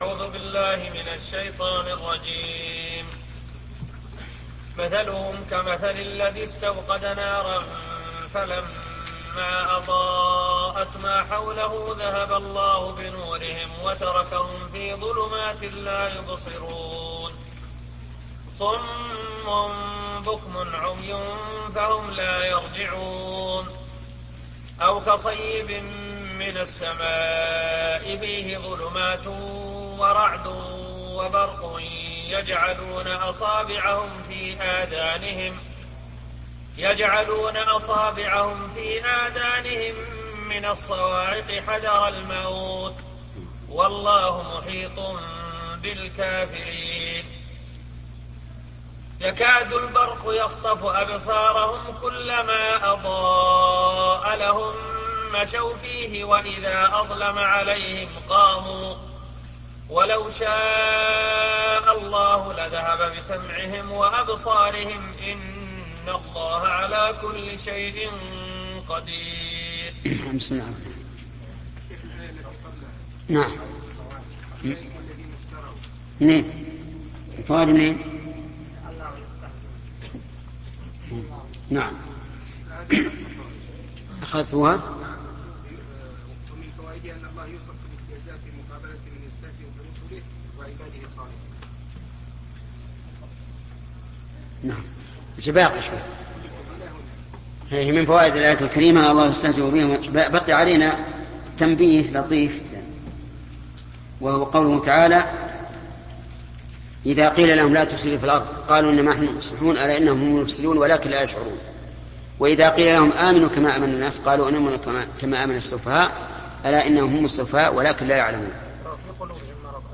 📌الشيخ محمد بن صالح العثيمين / تفسير القرآن الكريم